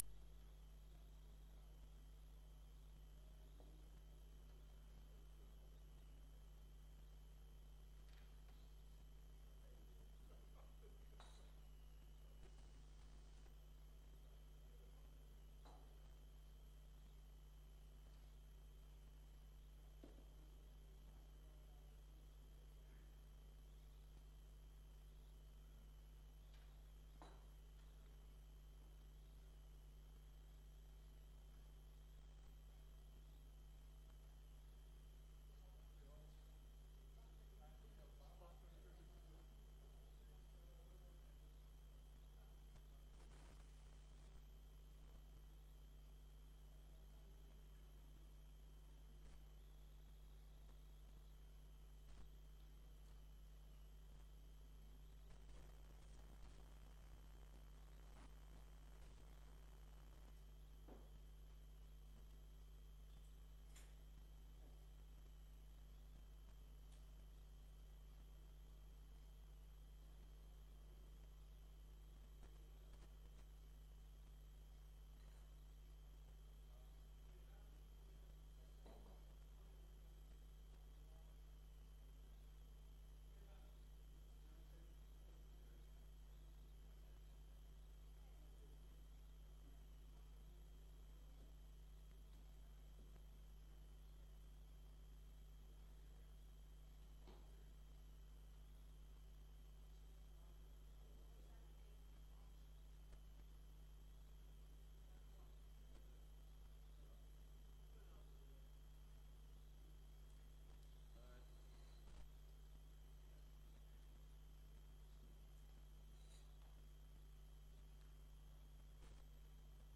Informatieve raadsvergadering 15 mei 2025 20:00:00, Gemeente Diemen
Locatie: Raadzaal